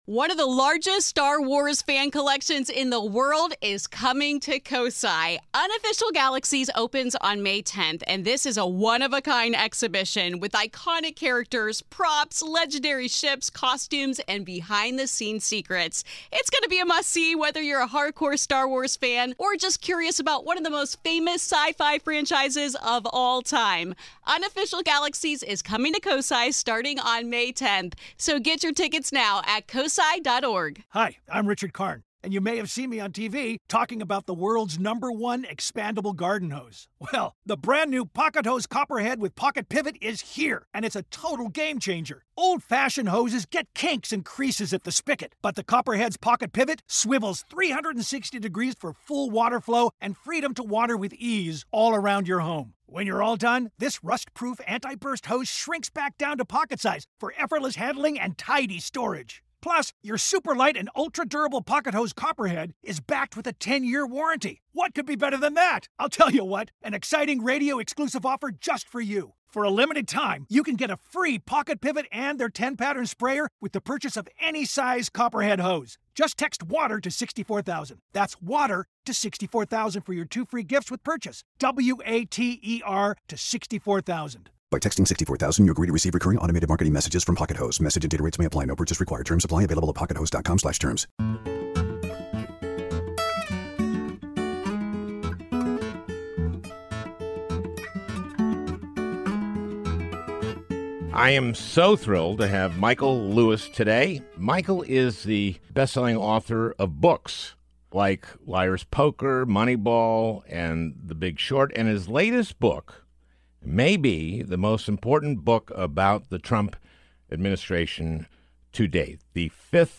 A Conversation with Michael Lewis